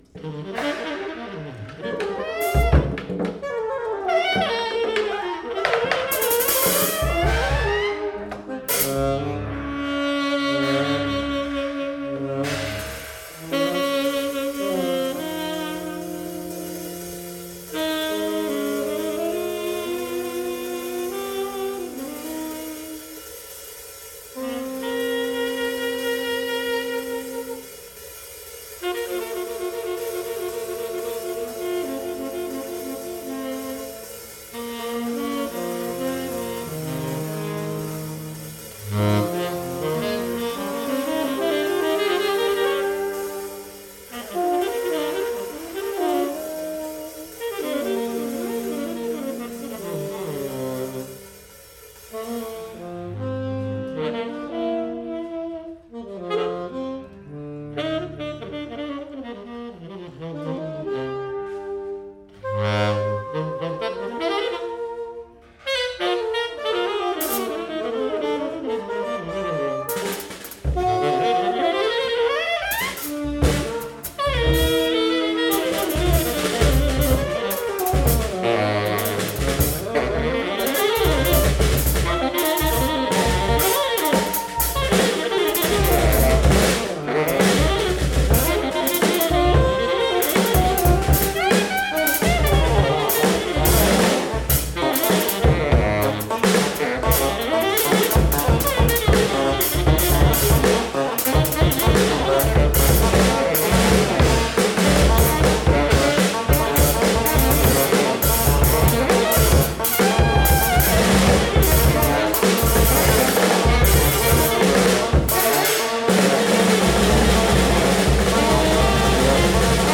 drums
alto saxophone, fx
tenor saxophone
Stereo (722 / Pro Tools)
stereo mic setup.